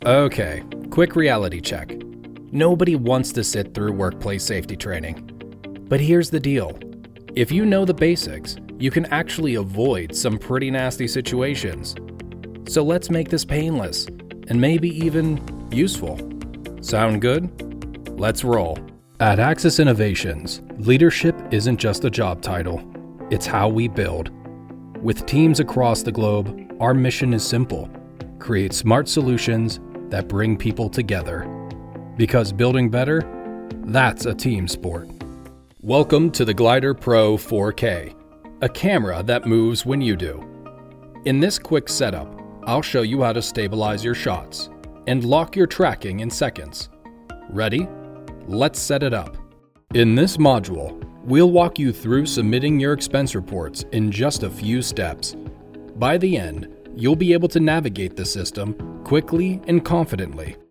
Male
Adult (30-50)
My voice is grounded, clear, and medium to medium-low in tone, ideal for authentic, conversational reads.
E-Learning
Engaging E-Learning Voice